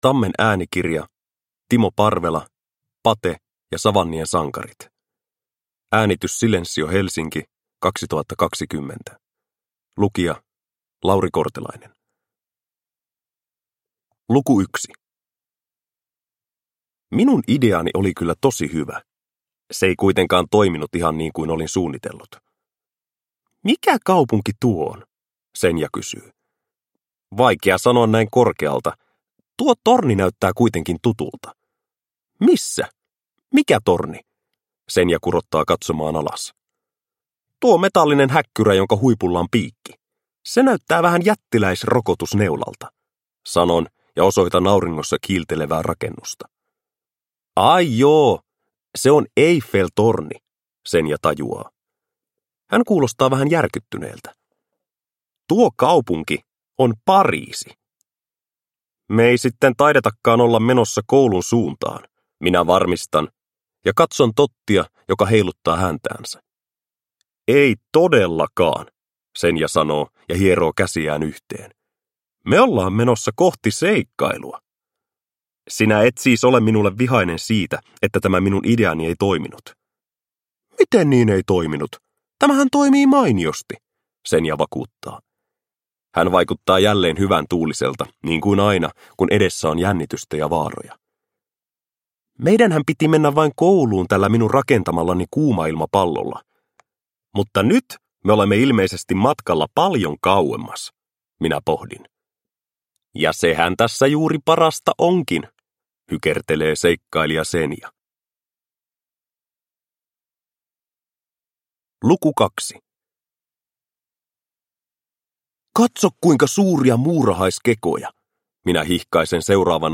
Pate ja savannien sankarit – Ljudbok – Laddas ner